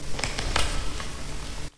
描述：通过用Adobe Audition处理废品而创造的声音设计
标签： 点击次数 毛刺 噪声 噪音大 加工 有节奏 2-酒吧 声音设计
声道立体声